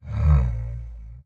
Minecraft Version Minecraft Version 1.21.5 Latest Release | Latest Snapshot 1.21.5 / assets / minecraft / sounds / mob / camel / ambient8.ogg Compare With Compare With Latest Release | Latest Snapshot